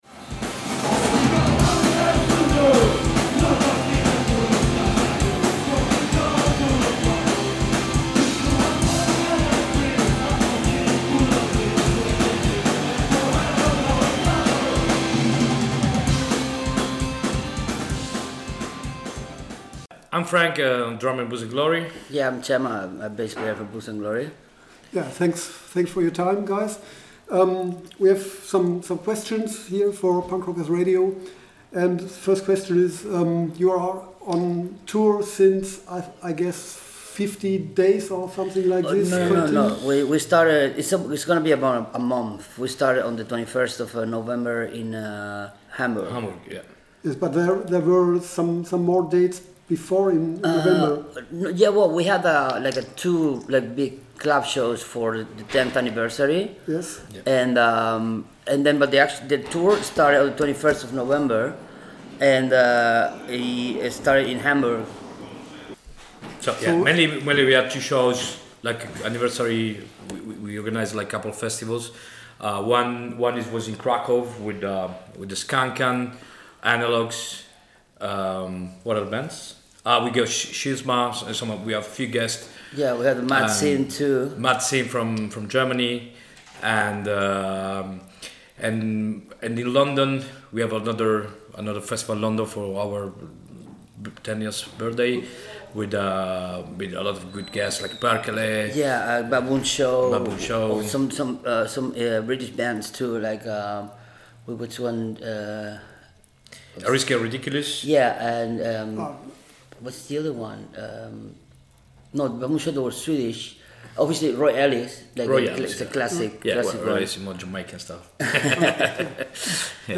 Letzte Episode Interview mit Booze & Glory 22.